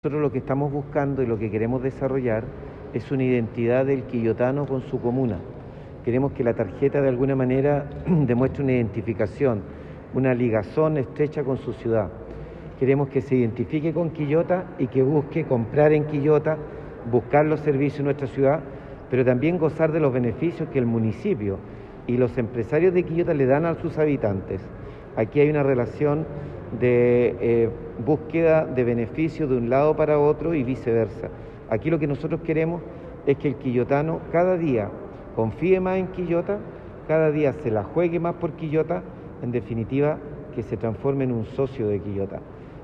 Por su parte, el alcalde de Quillota, doctor Luis Mella Gajardo, quien también participó en el lanzamiento, afirmó que a través de “Quillota más cerca” se busca vincular la identidad del quillotano con su comuna.
Alcalde-Luis-Mella.mp3